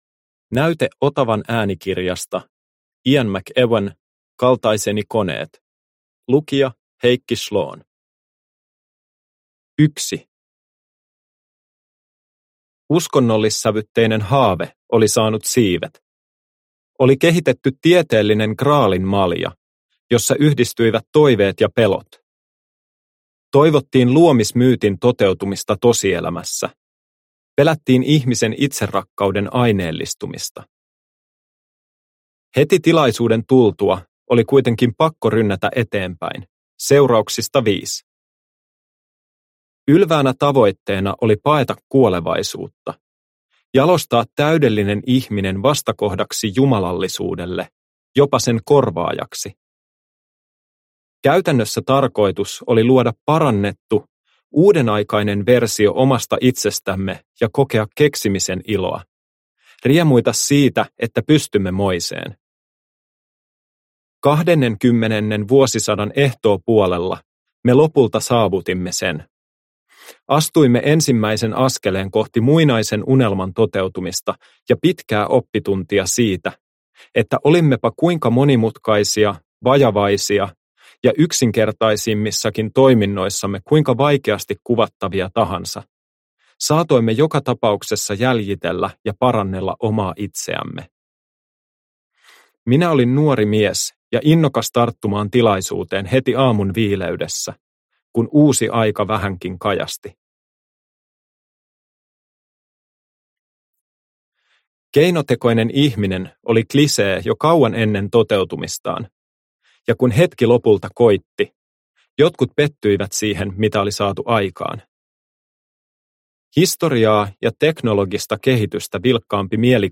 Kaltaiseni koneet – Ljudbok – Laddas ner